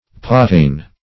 pottain - definition of pottain - synonyms, pronunciation, spelling from Free Dictionary Search Result for " pottain" : The Collaborative International Dictionary of English v.0.48: Pottain \Pot"tain\, n. Old pot metal.
pottain.mp3